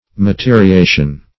Search Result for " materiation" : The Collaborative International Dictionary of English v.0.48: Materiation \Ma*te`ri*a"tion\, n. [L. materiatio woodwork.] Act of forming matter.
materiation.mp3